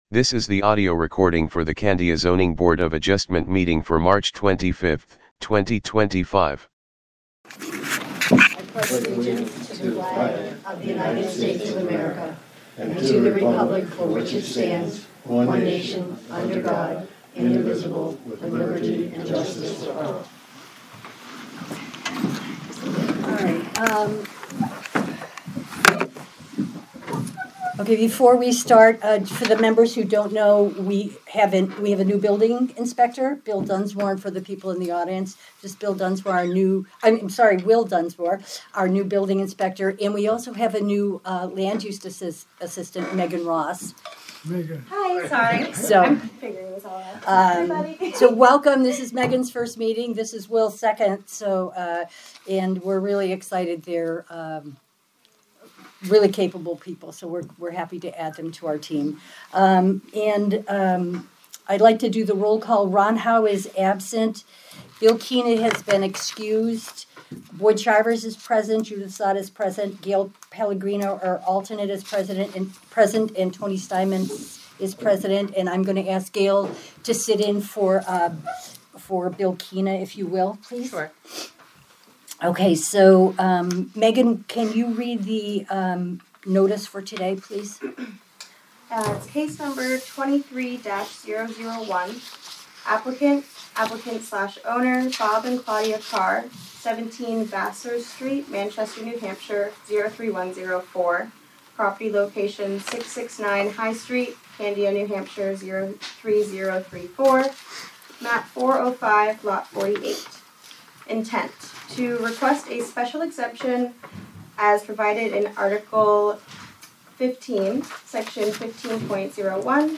Audio recordings of committee and board meetings.
Zoning Board of Adjustment Meeting